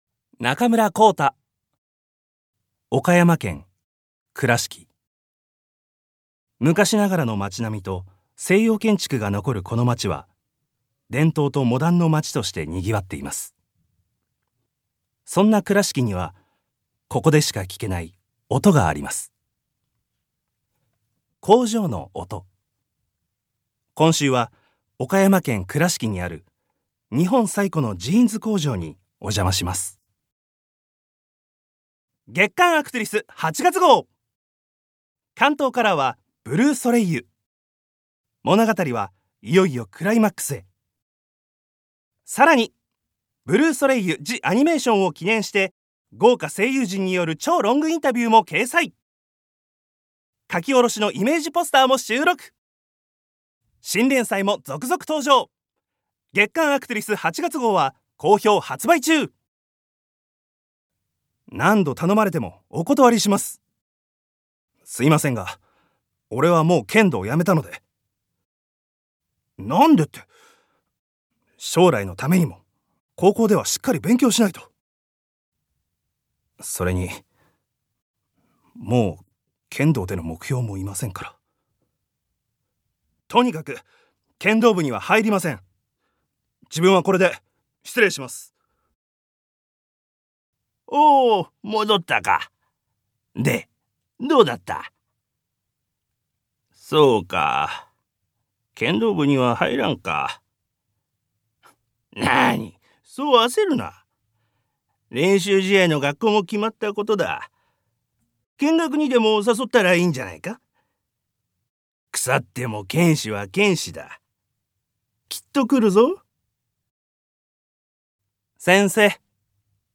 所属：サロンドアクトゥリス 出身：東京生年月日：6月22日星座：蟹座血液型：B型身長：182cm One shot Voice（サンプルボイスの視聴）